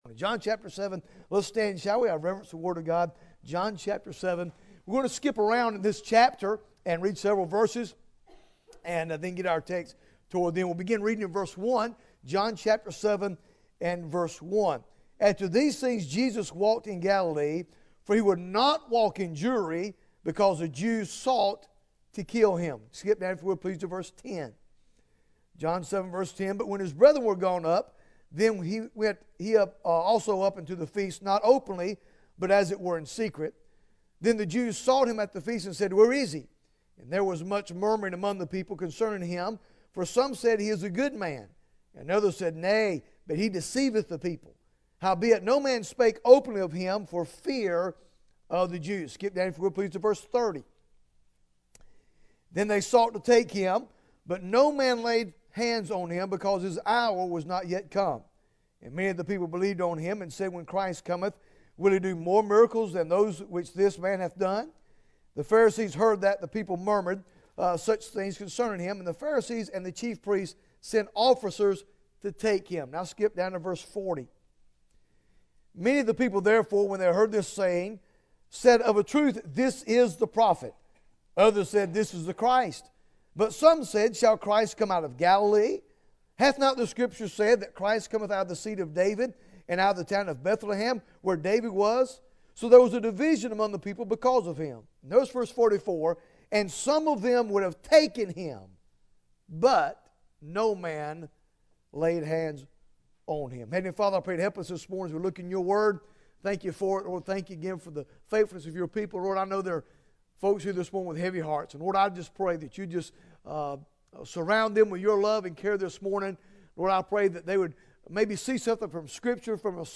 Bible Text: John 7 | Preacher